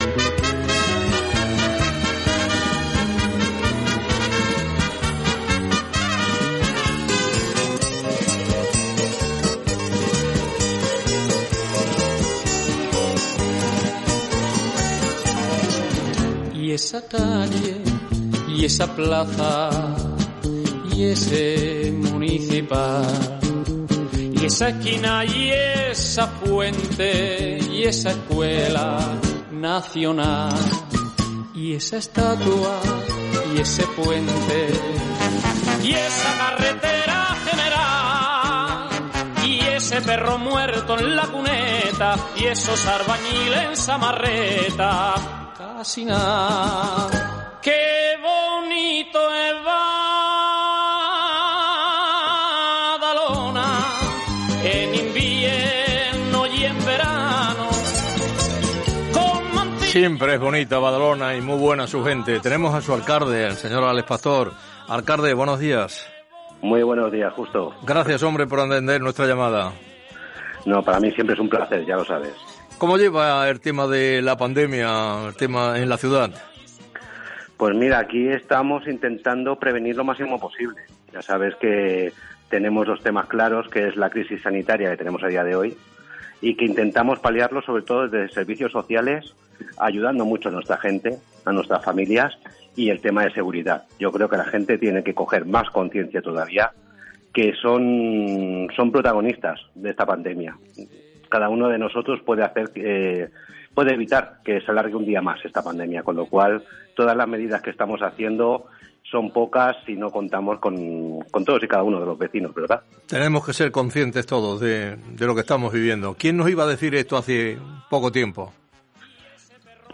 entrevista-tlf-alcalde-bdn.mp3